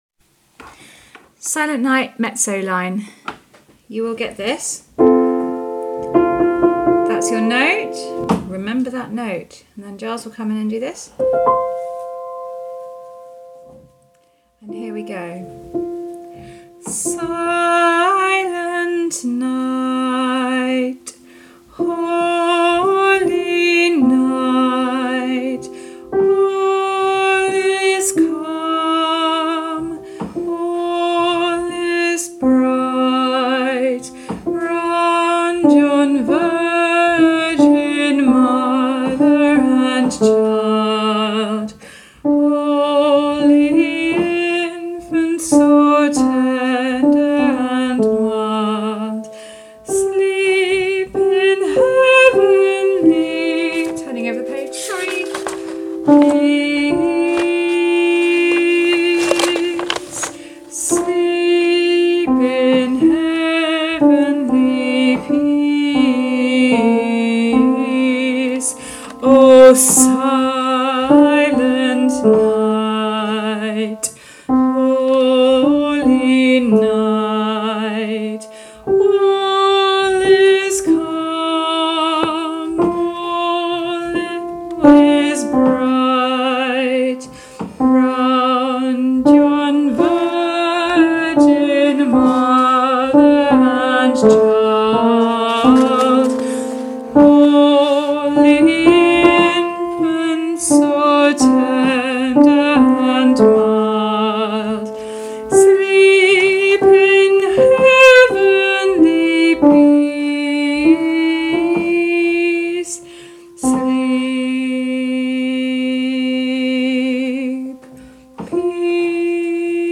Mezzos
Silent-Night-Mezzos.mp3